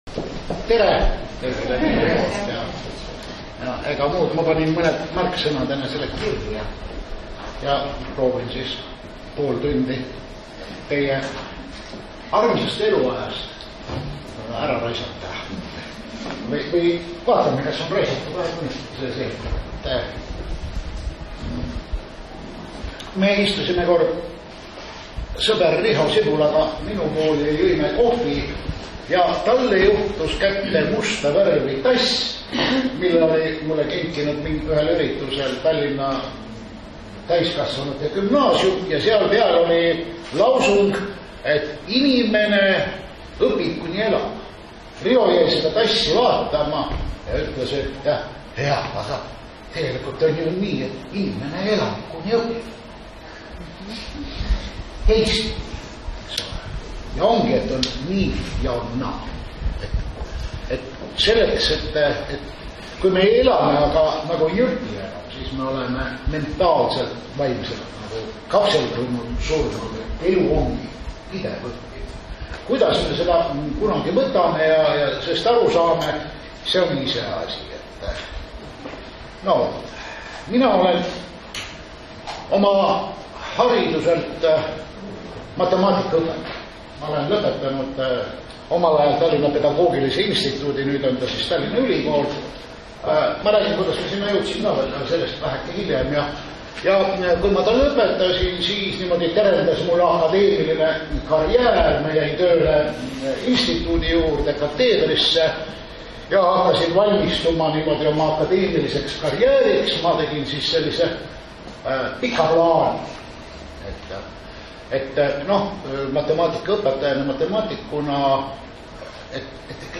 6. novembril 2015 toimus Tallinna Õpetajate Majas XI täiskasvanud õppijate foorum (TÕF).
Ettekandega teemal „Inimene elab, kuni õpib“ esines Vladislav Koržets; kirjanik, ajakirjanik, humorist, saatejuht.